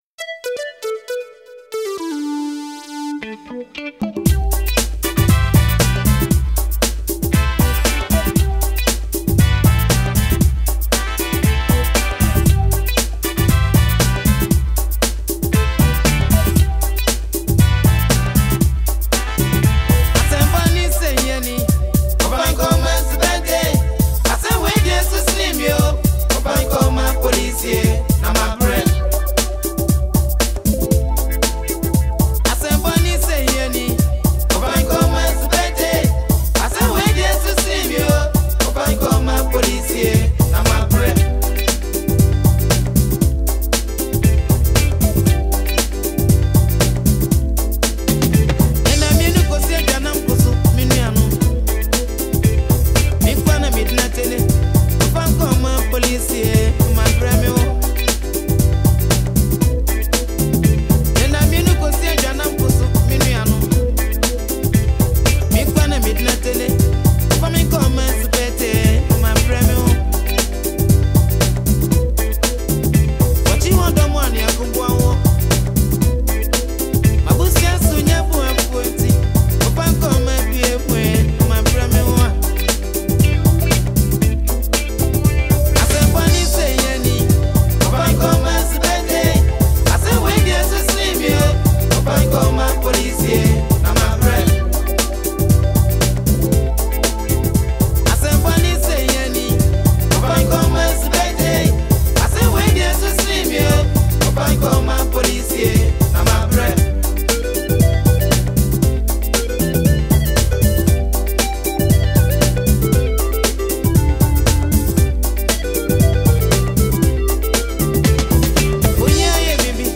smooth and soulful vocals